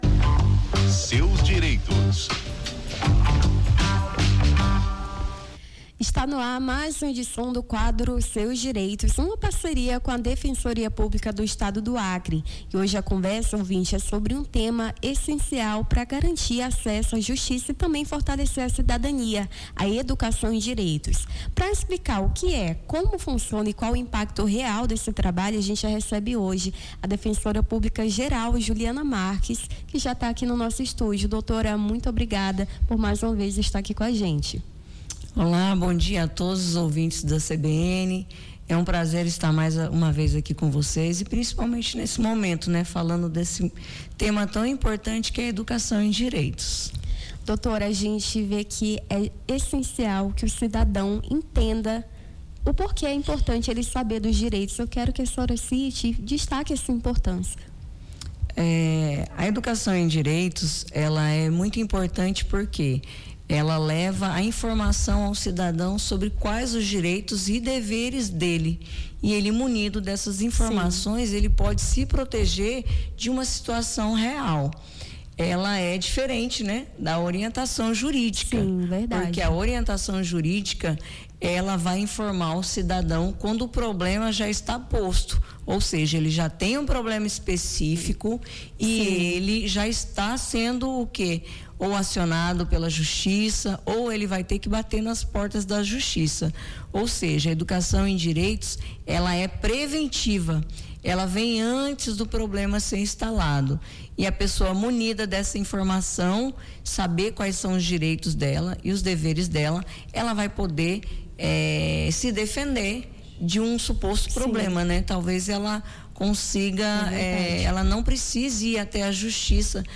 conversou com Juliana Marques, defensora pública-geral, sobre educação em direitos.